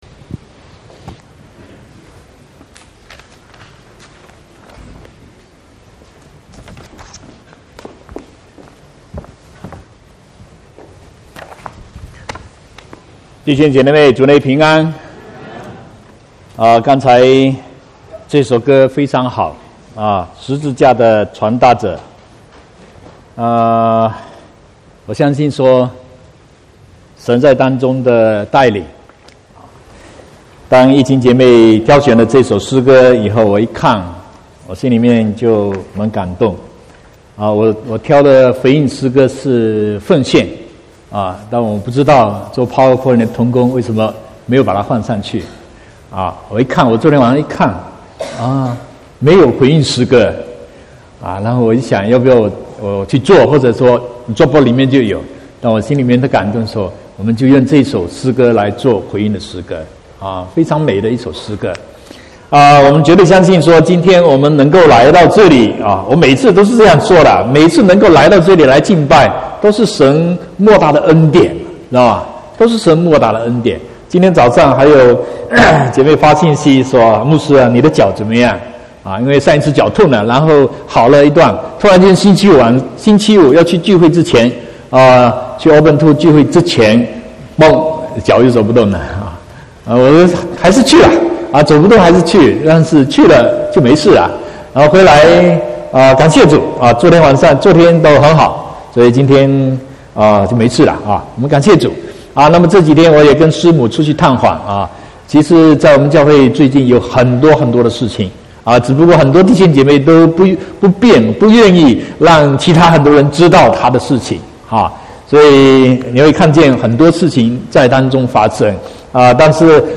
18/11/2018 國語堂講道